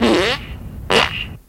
文件夹里的屁 " 屁 45
描述：从freesound上下载CC0，切片，重采样到44khZ，16位，单声道，文件中没有大块信息。
Tag: 喜剧 放屁 效果 SFX soundfx 声音